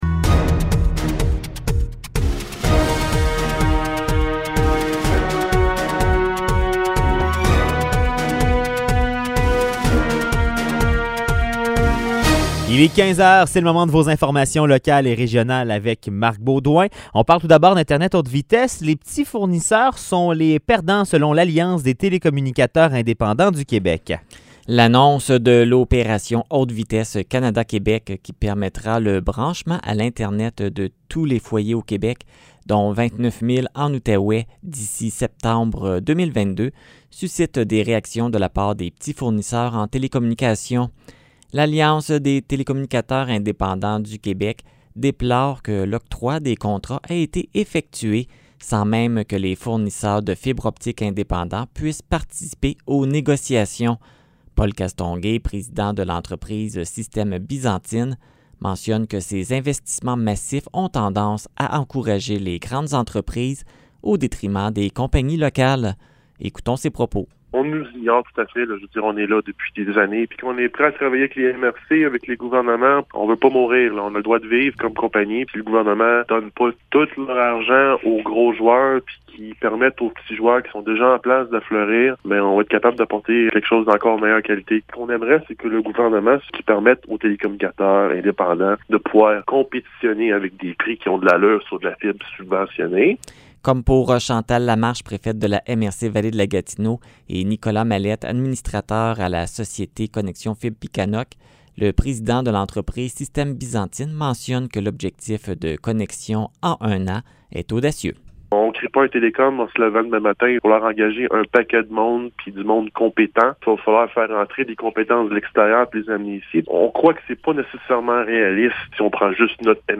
Nouvelles locales - 26 mars 2021 - 15 h